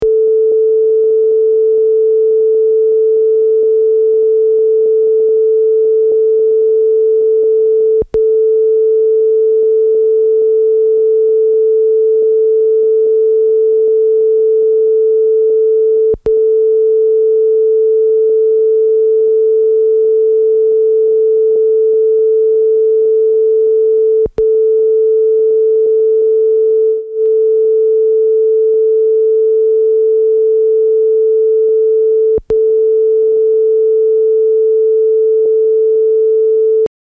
Unfortunately I don't have a test or alignment tape, so I've been recording a 440hz sine on all 8 channels at once for a few minutes and listen back.